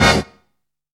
TITLE STAB.wav